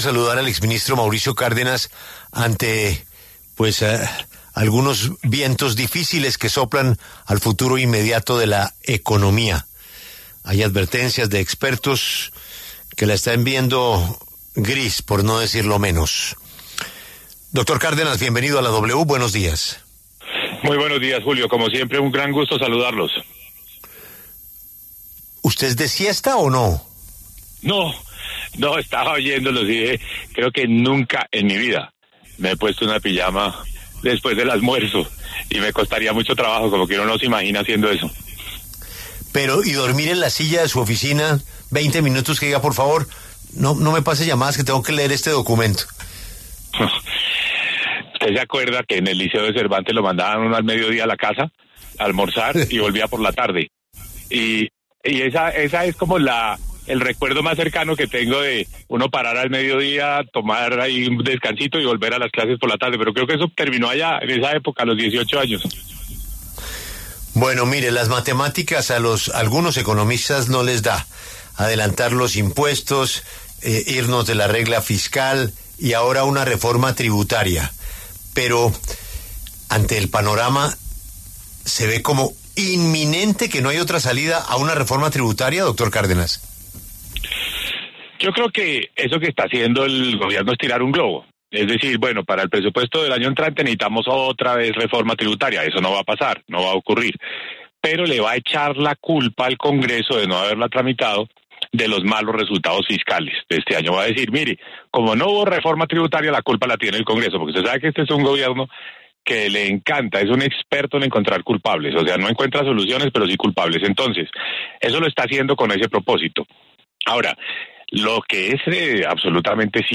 Mauricio Cárdenas, exministro de Hacienda, habló en La W sobre la hoja de ruta fiscal con la que el Gobierno pretende recaudar más ingresos.